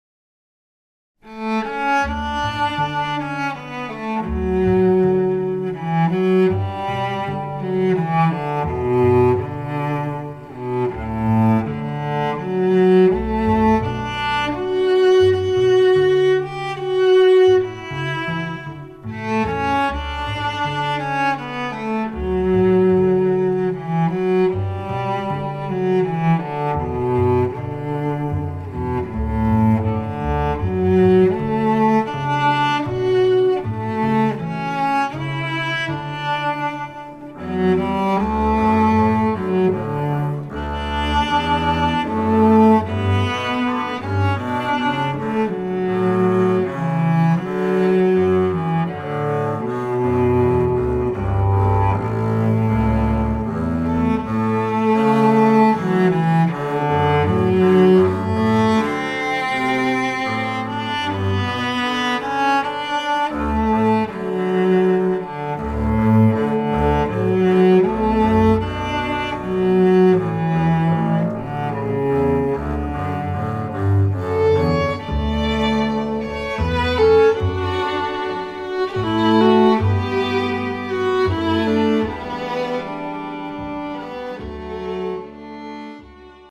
Bass
Fiddle
Cello
guitar